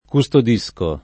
vai all'elenco alfabetico delle voci ingrandisci il carattere 100% rimpicciolisci il carattere stampa invia tramite posta elettronica codividi su Facebook custodire v.; custodisco [ ku S tod &S ko ], -sci — non costudire